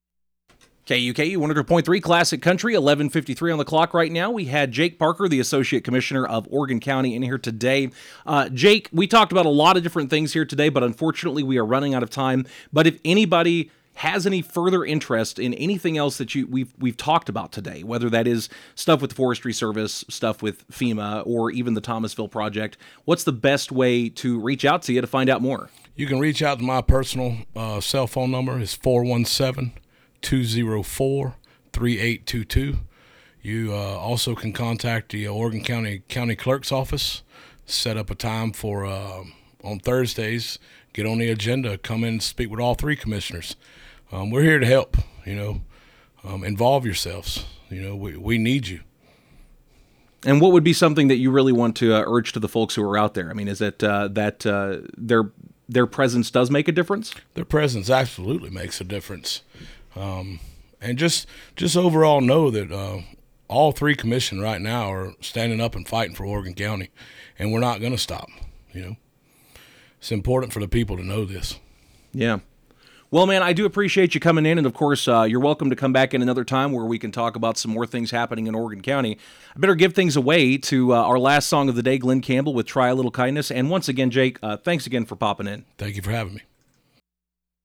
Oregon County Commissioner Jake Parker in the studio of KUKU on February 2nd, 2026.